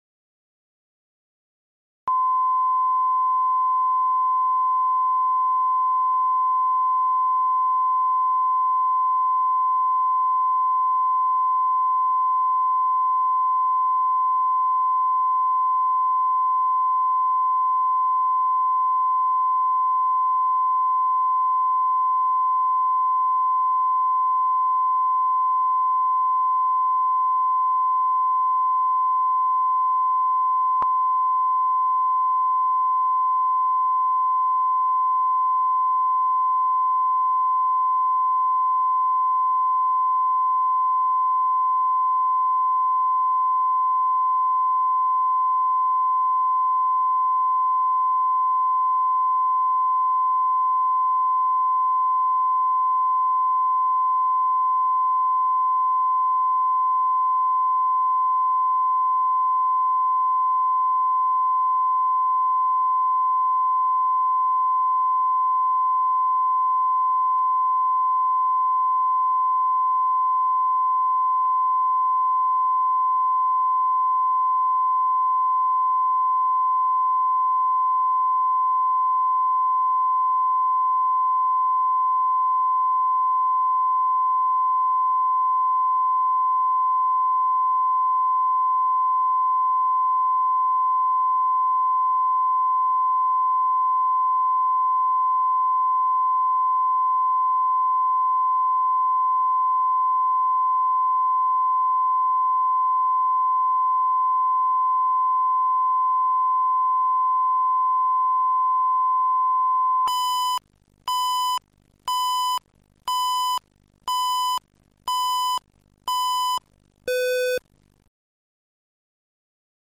Аудиокнига Ремонт по соседству | Библиотека аудиокниг